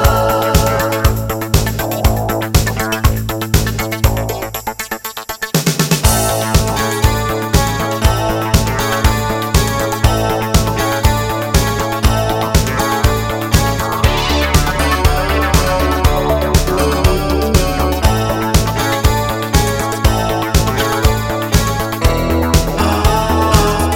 Pop (1980s) 2:44 Buy £1.50